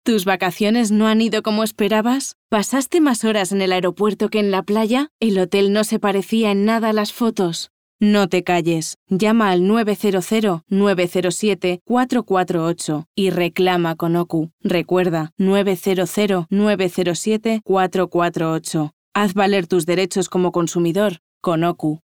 Voz natural, profunda o ligera, vesátil, con capacidad de crear diferentes registros.
kastilisch
Sprechprobe: Industrie (Muttersprache):
Natural voice, deep or gentle, versatile, able to create different vocal ranges.